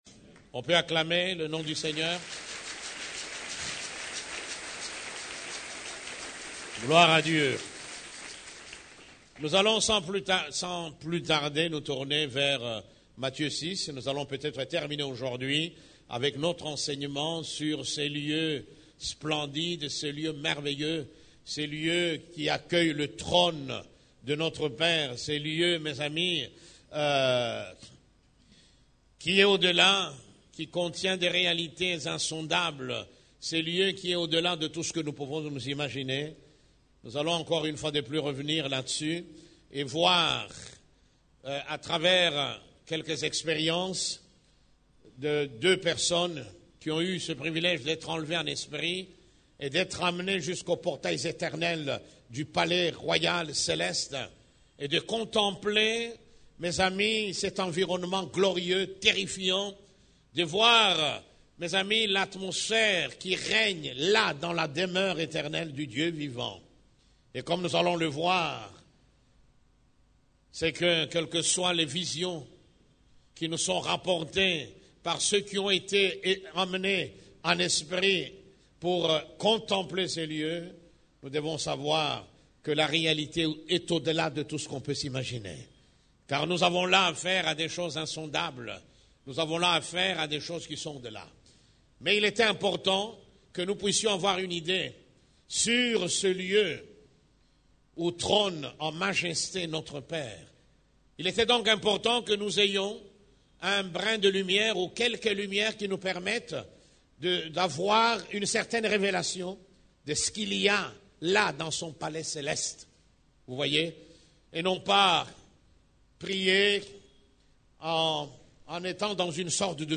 CEF la Borne, Culte du Dimanche, L'entrée du lieu secret 9